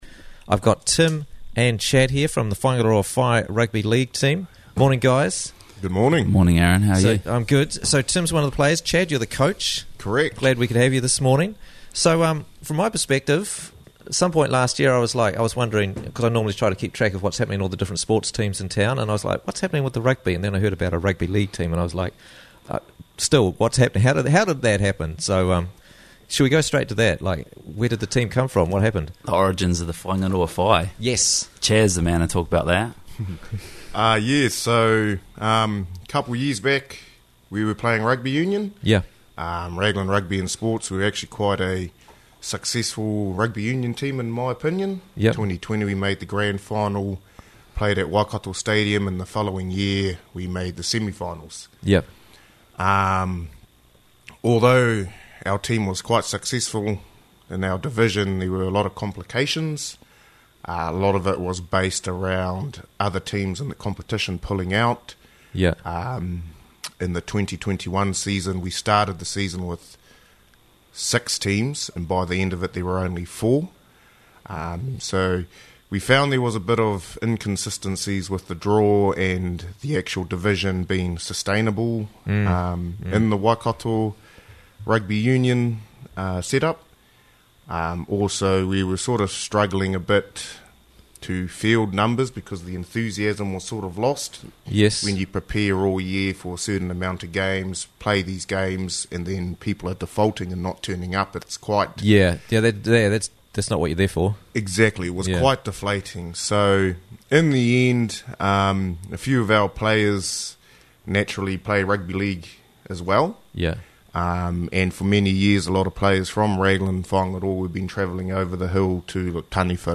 The Whaingaroa Whai Story - Interviews from the Raglan Morning Show